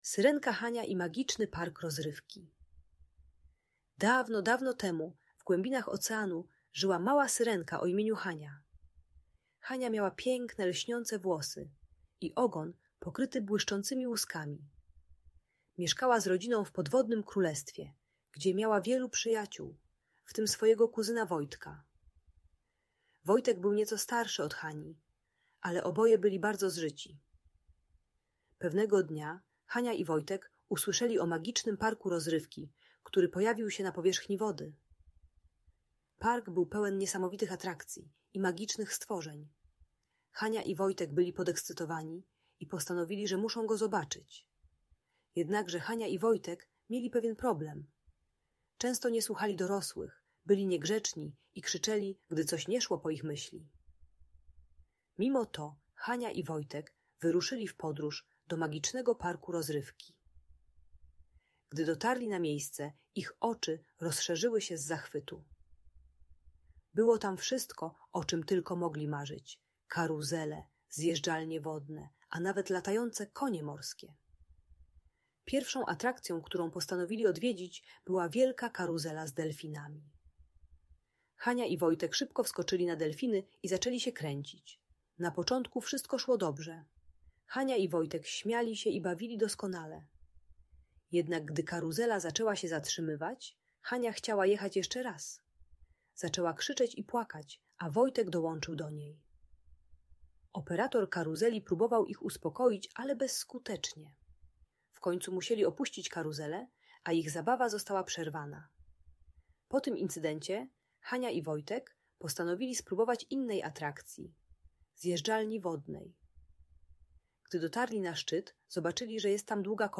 Syrenka Hania i Magiczny Park Rozrywki - Bunt i wybuchy złości | Audiobajka